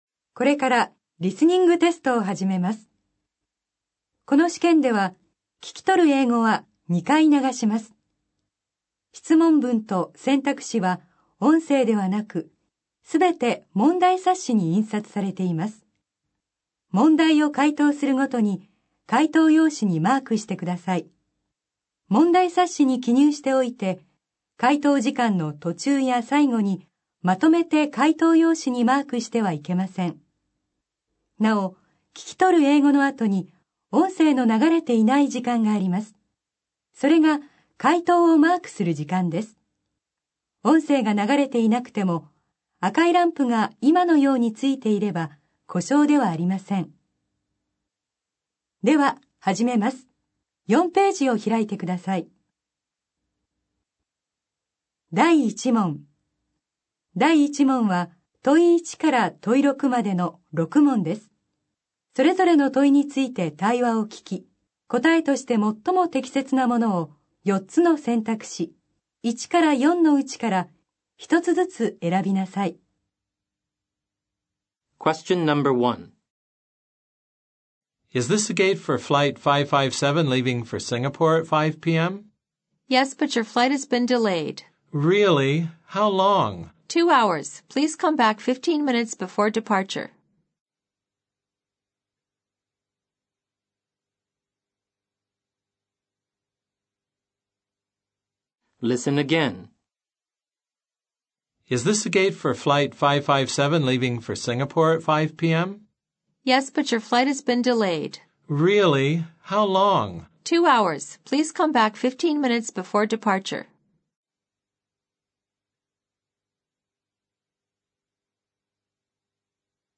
英語リスニング 過去問の全て